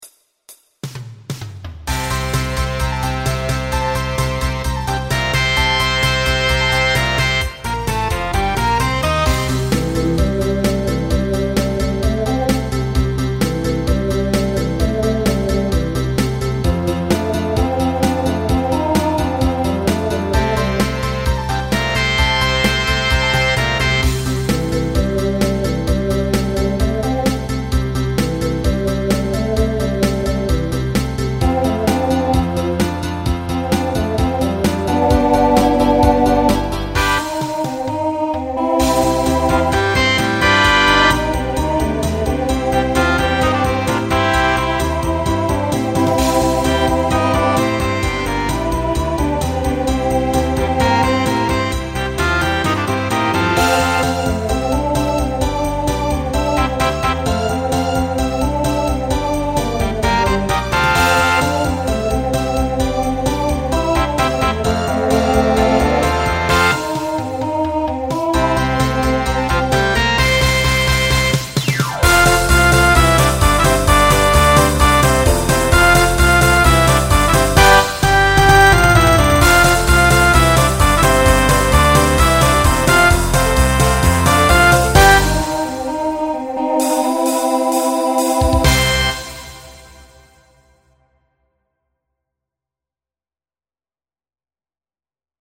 Genre Country Instrumental combo
Novelty , Transition Voicing TTB